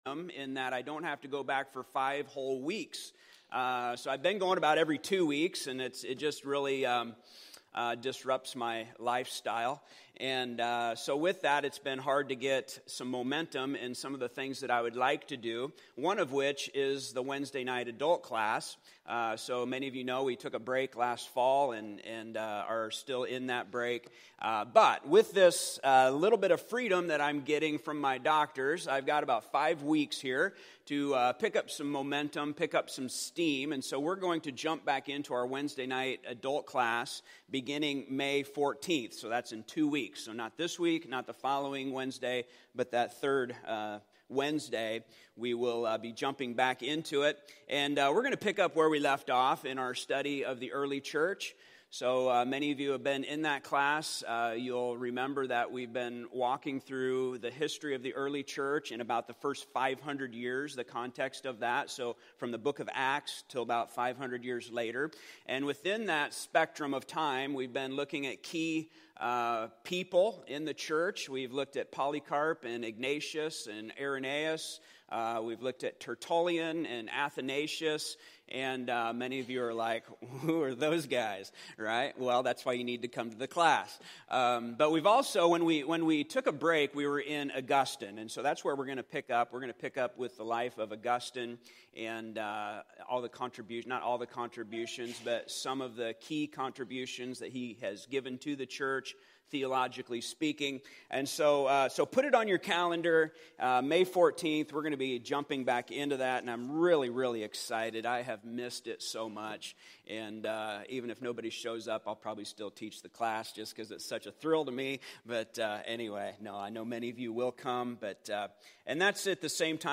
Letter to the Romans Passage: Romans 5:12-19 Service Type: Sunday Morning Topics